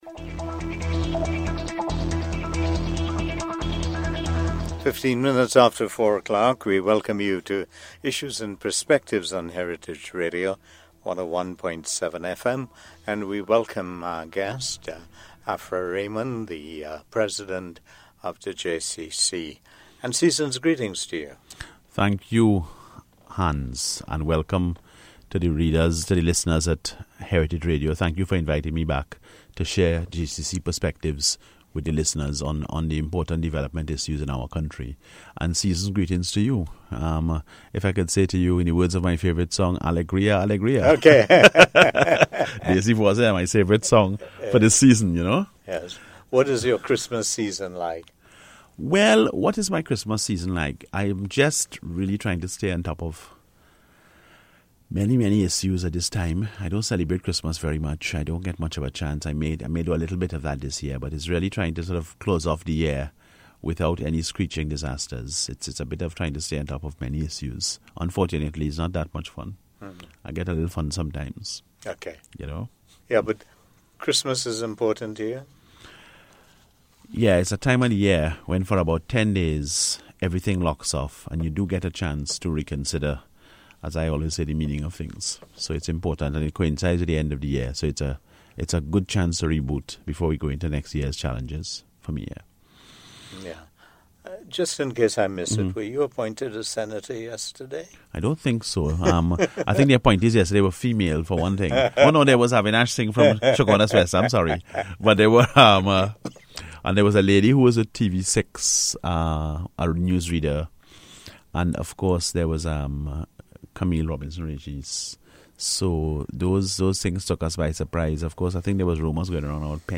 AUDIO: Heritage Radio Interview: Public Procurement, etc. – 04 December 2013